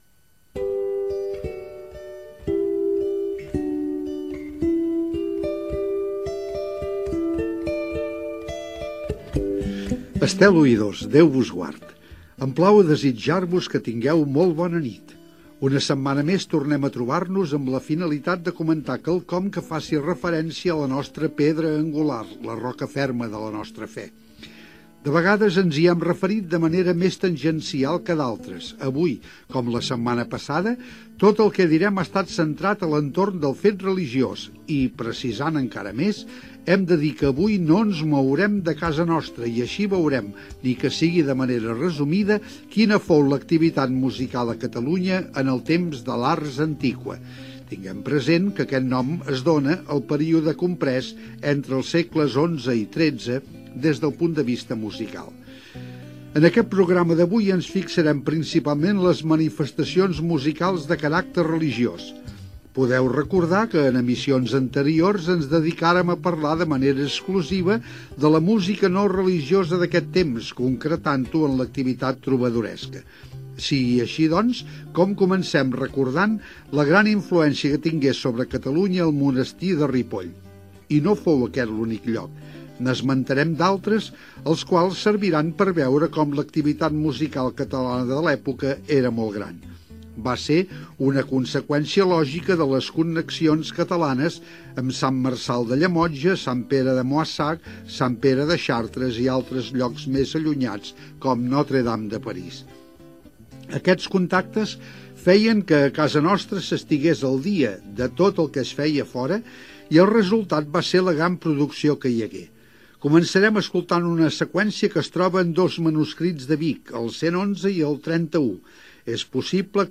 Presentació del programa dedicat a l'Ars Antiqua, tema musical
Divulgació
FM